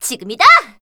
assassin_w_voc_dirtytrick02.ogg